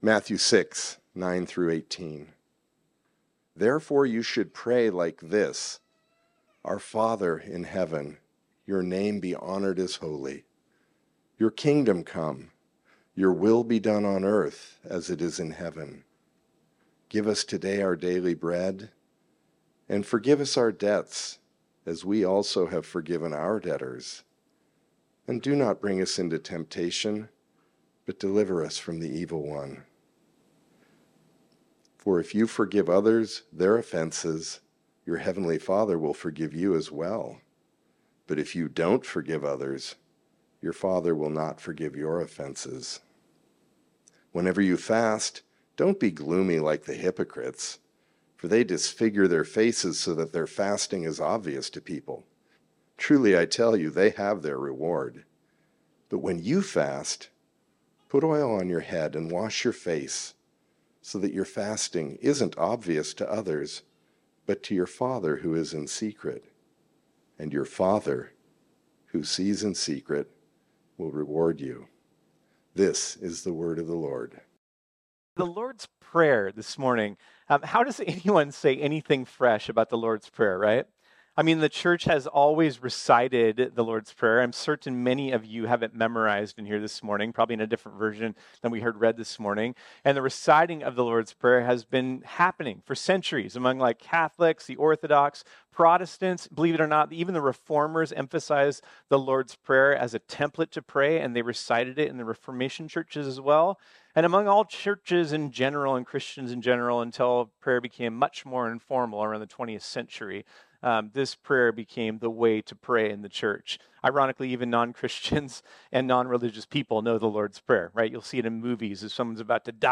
This sermon was originally preached on Sunday, March 9, 2025.